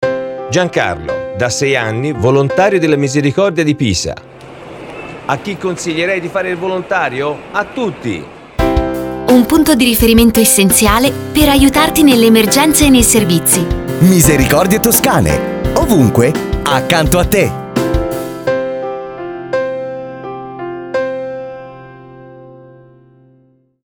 Si tratta di 4 spot con confratelli provenienti da varie parti della toscana
172-campagna-spot-radiofonico-pisa.html